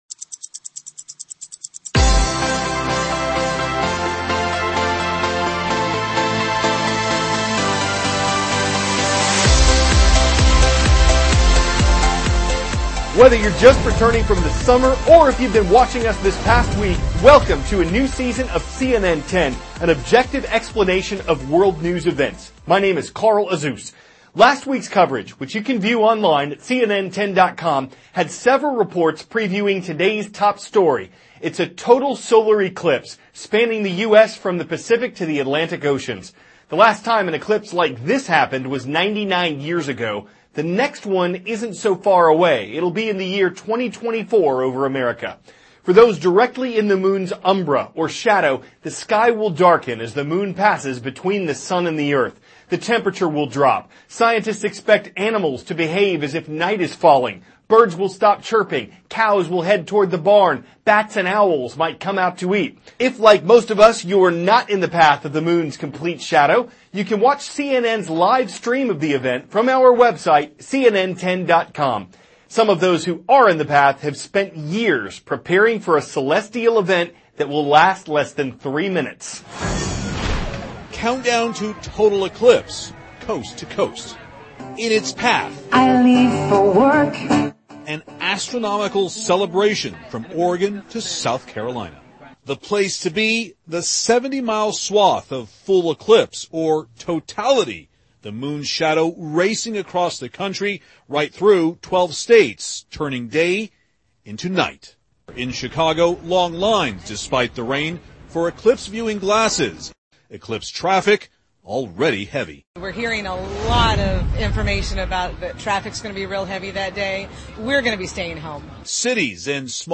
CARL AZUZ, cnn 10 ANCHOR: Whether you`re just returning from the summer or if you`ve been watching us this past week, welcome to a new season of cnn 10, an objective explanation of world news events.